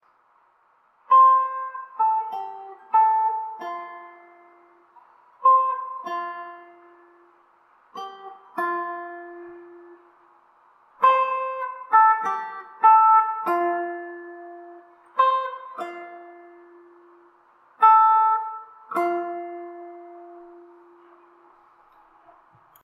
But I did make some music in the last few hours. It's not my greatest work, but it sounds decent and it's loopable.